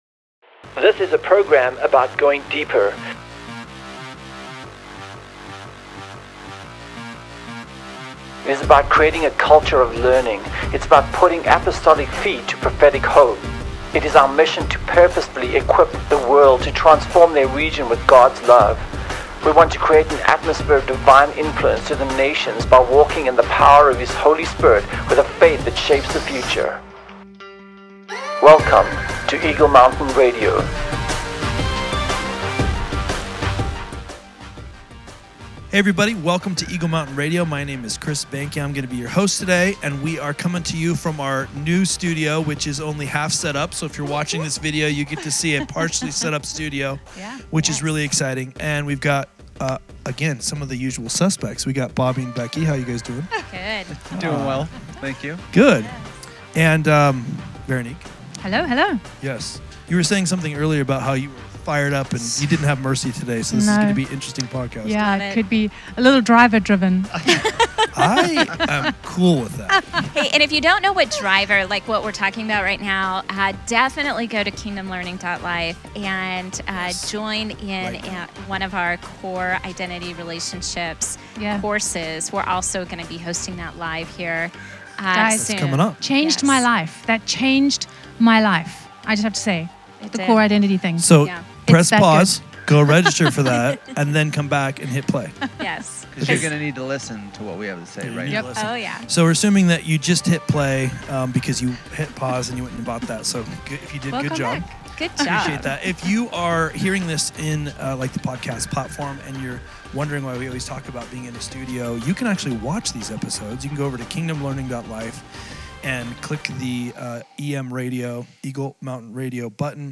Somehow he made it from the palace to leading the Israelites out of Egypt. Our panel discusses Peter’s process as well.